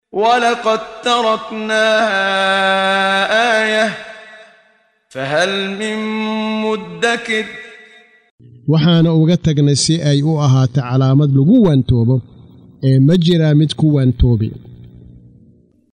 Waa Akhrin Codeed Af Soomaali ah ee Macaanida Suuradda Al-Qamar ( Dayaxa ) oo u kala Qaybsan Aayado ahaan ayna la Socoto Akhrinta Qaariga Sheekh Muxammad Siddiiq Al-Manshaawi.